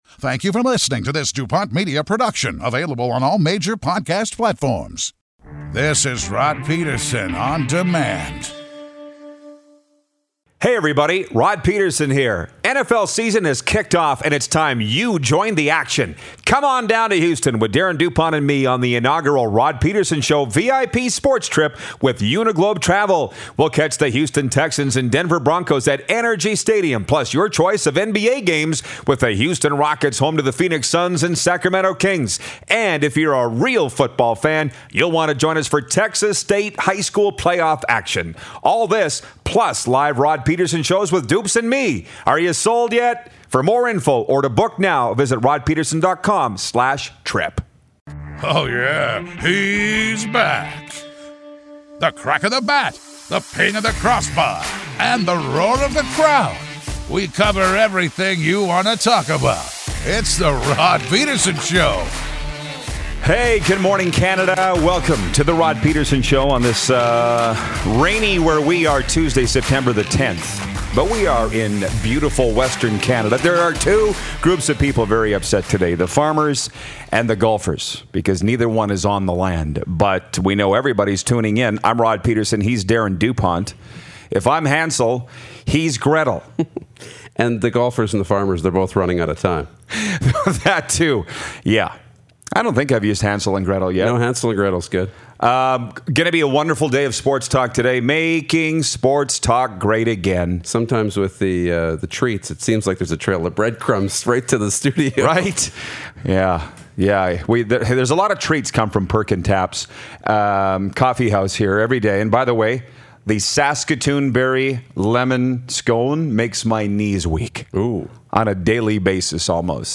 Canadian Football Hall of Famer and Calgary Stampeders Legend Jon Cornish calls in!